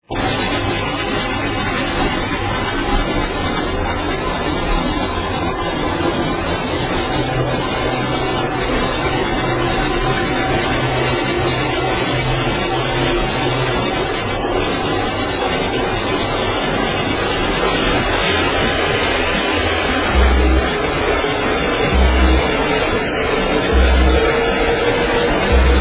very hard to id u cant hear it to well
The quality is awful...